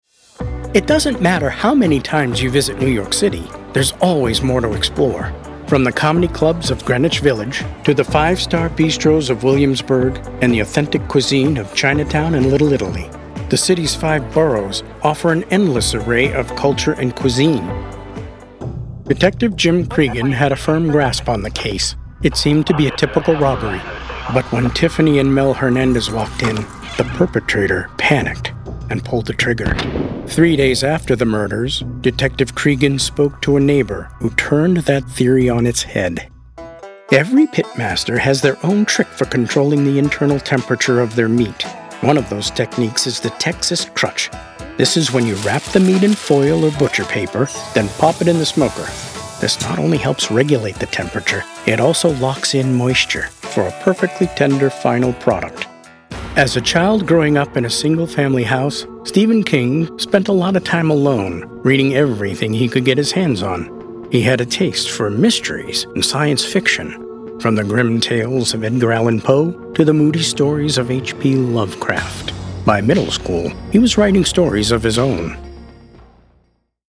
Narration
Narration Demo.mp3